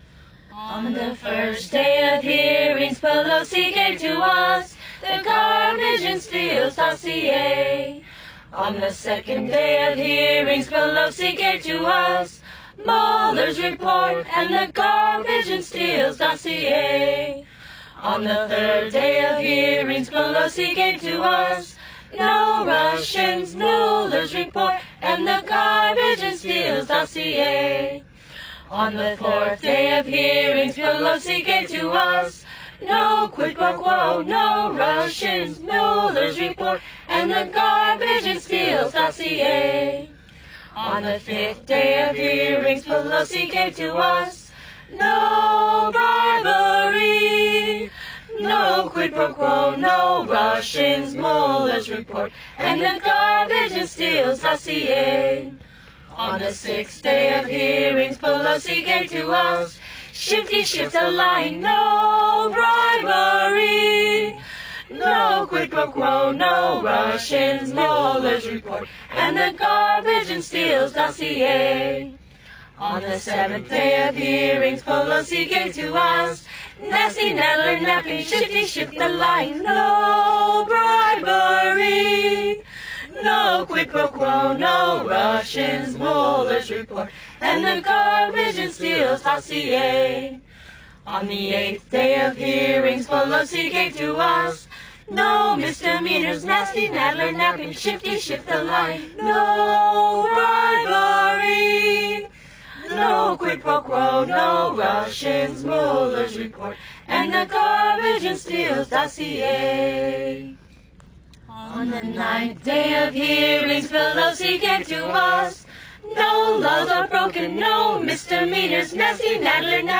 PUBLIC ADVOCATE Political Christmas Carols
parody Christmas Carols